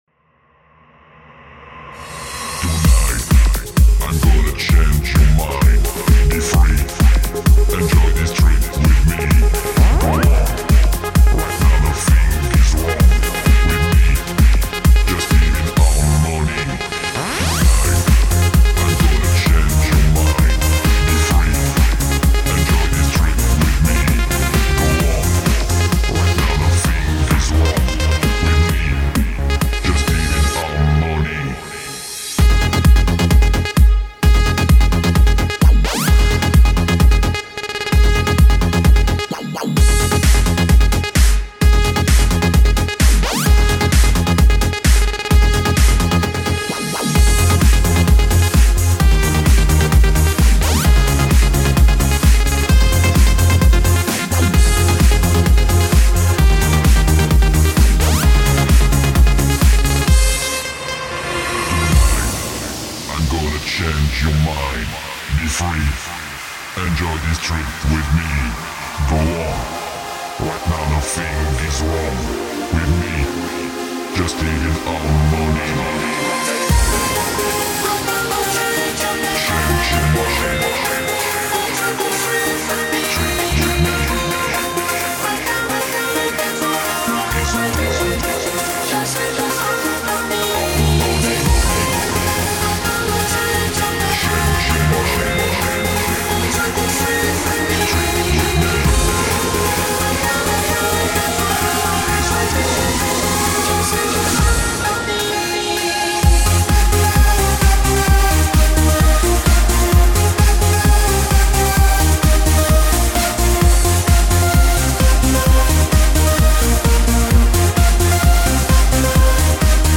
Стиль:Dance